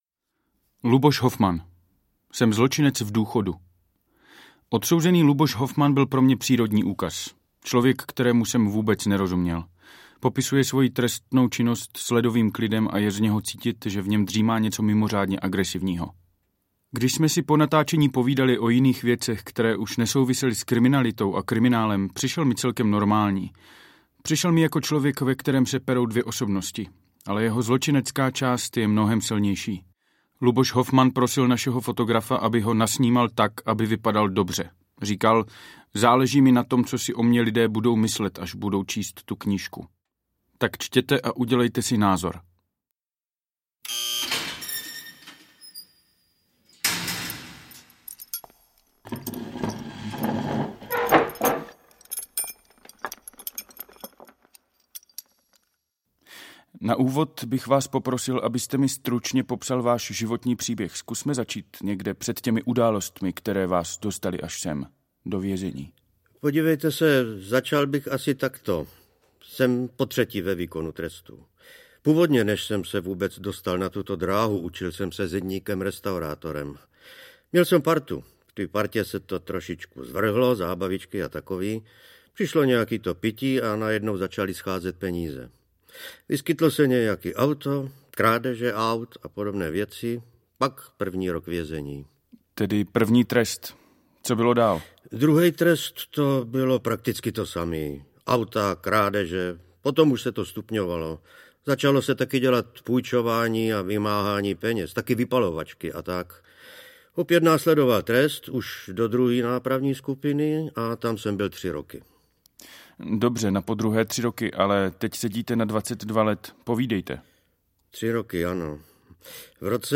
Jak se vraždí v Čechách audiokniha
Ukázka z knihy